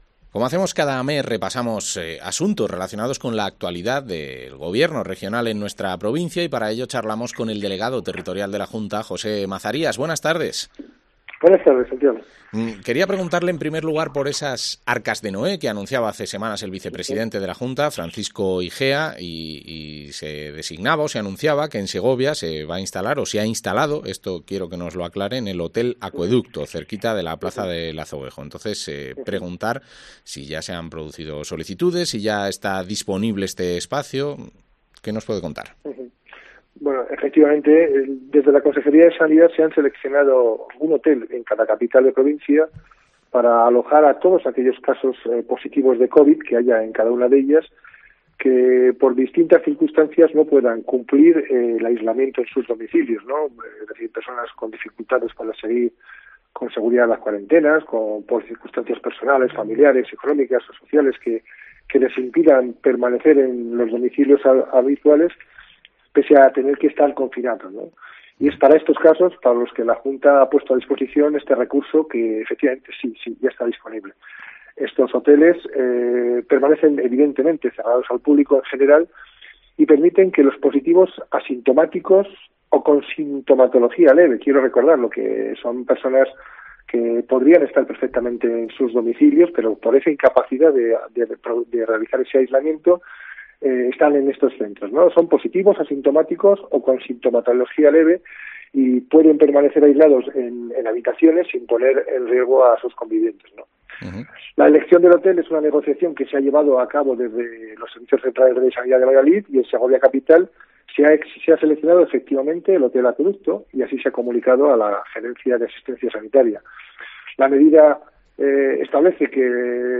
Entrevista al delegado territorial de la Junta, José Mazarías